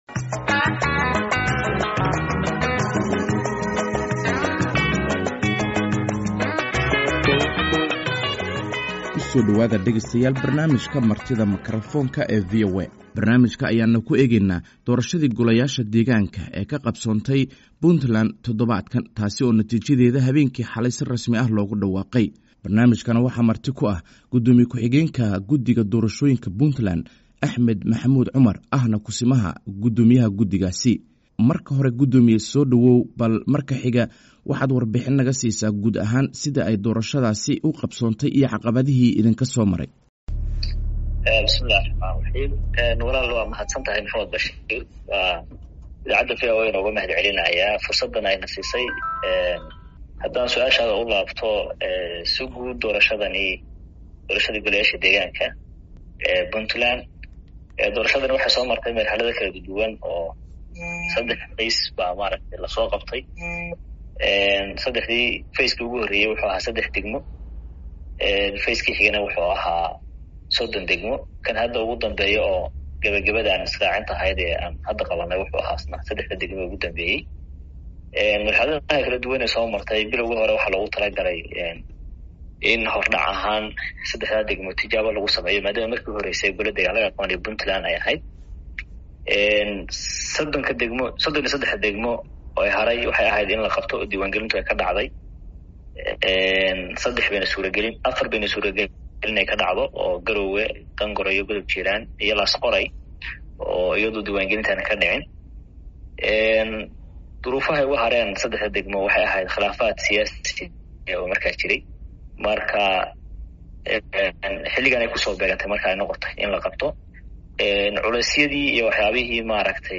Barnaamijka oo uu marti ku tahay Axmed Maxamuud Cumar oo ah Ku-simaha Guddoomiyaha Guddiga Doorashooyinka Puntland (PEC) oo ka hadlaya sidii ay u dhacday doorashadii goleyaasha deegaanka